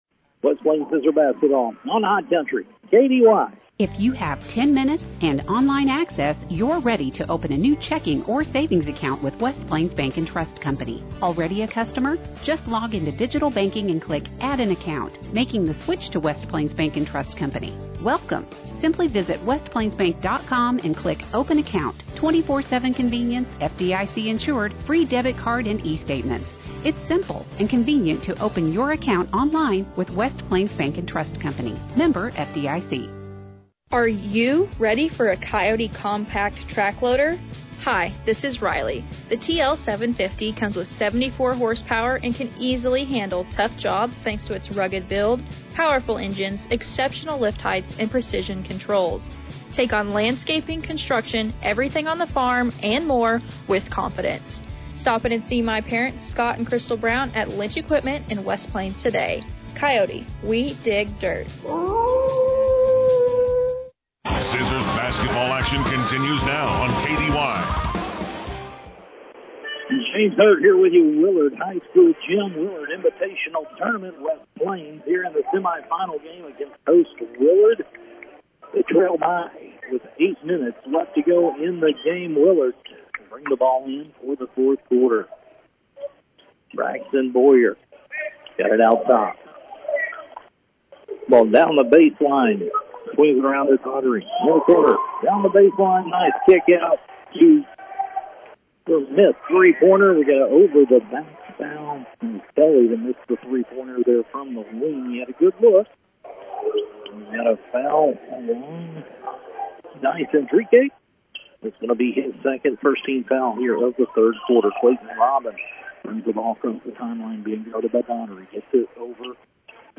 Game Audio Below: The West Plains Zizzer Boys Basketball Team played their Second game on Friday December 6th in the 2024 Willard Basketball Classic versus Willard, the Host team..